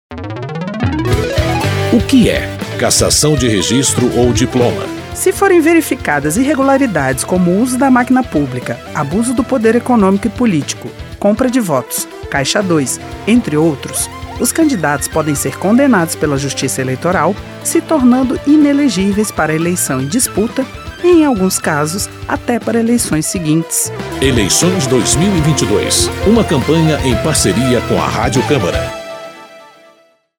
São 13 spots de 30 segundos.